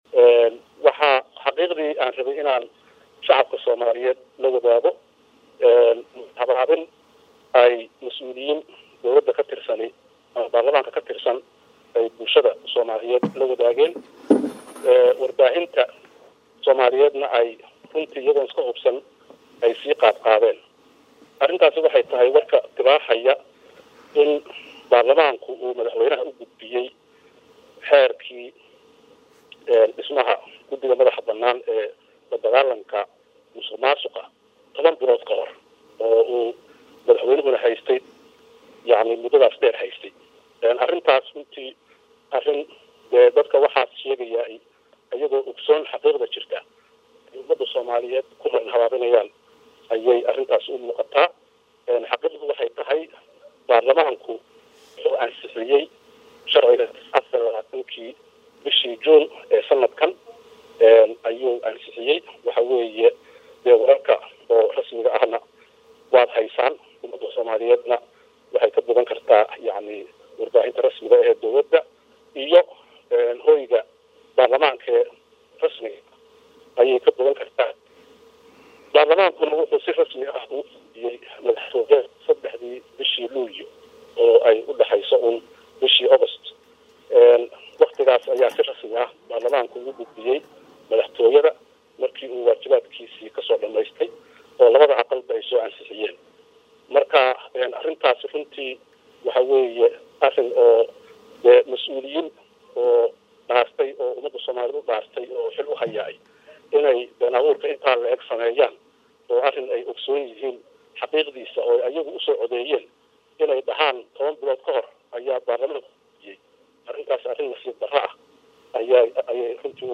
Guddoomiye ku xigeenka Labaad ee Golaha Shacabka ee Baarlamaanka ee Jamhuuriyadda Federaalka Soomaaliya Mudane Mahad Cabdalla Cawad oo Wareysi siiyey